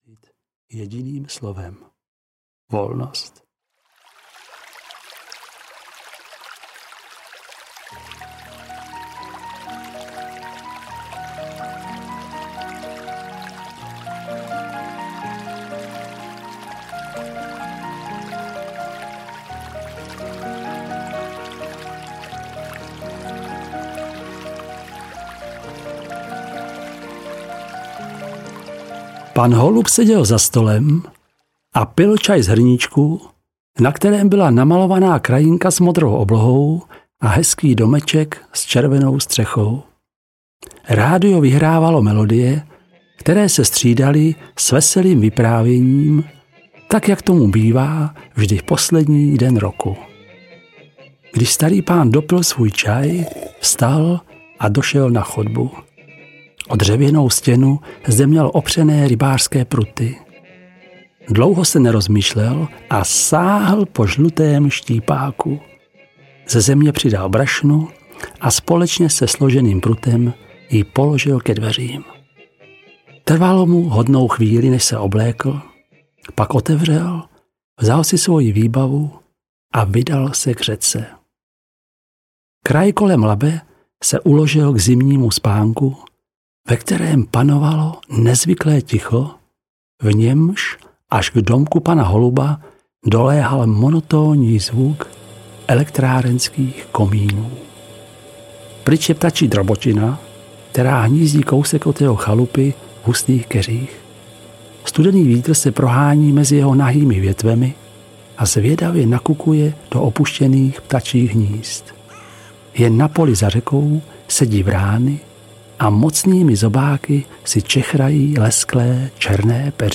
Štičí silvestr audiokniha
Ukázka z knihy